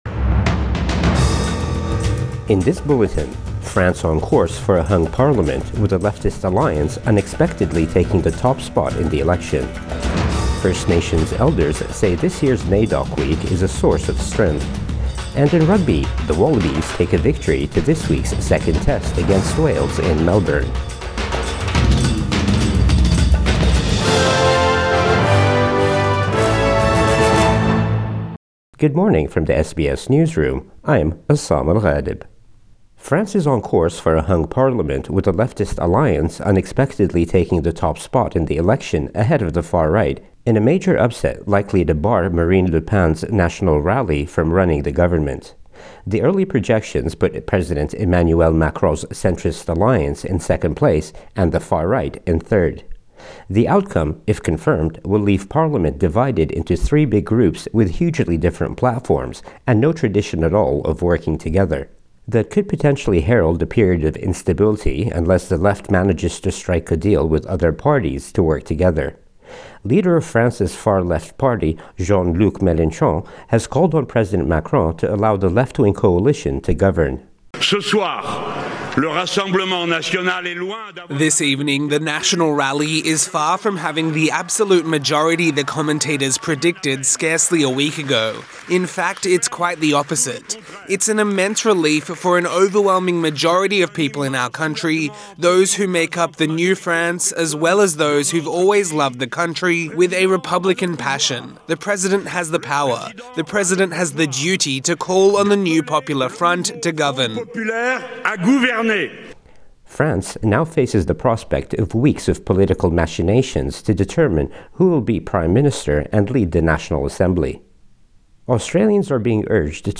Morning News Bulletin 8 July 2024